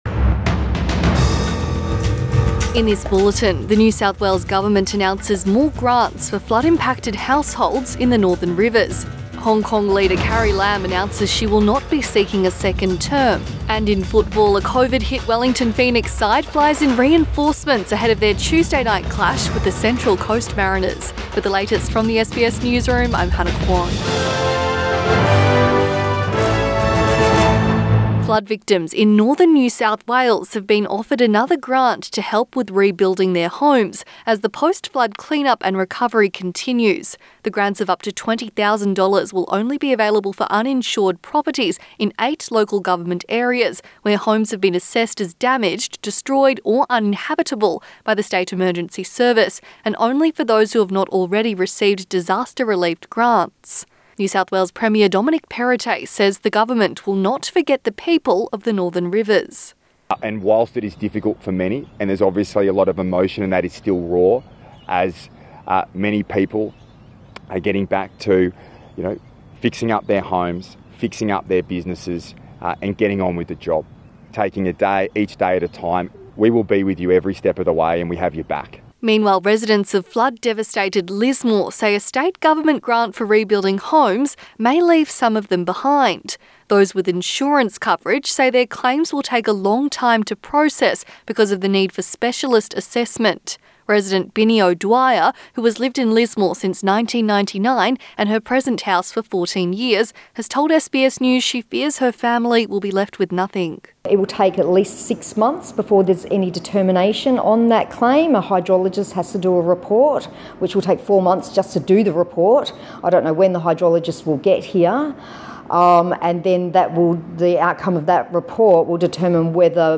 PM bulletin 4 April 2022